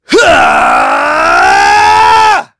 Clause_ice-Vox_Casting4.wav